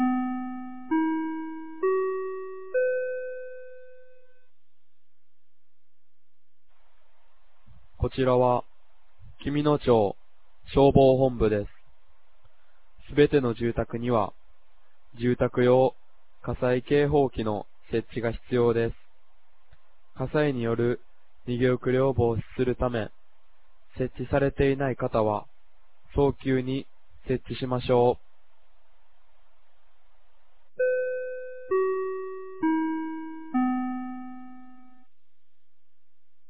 2025年08月02日 16時00分に、紀美野町より全地区へ放送がありました。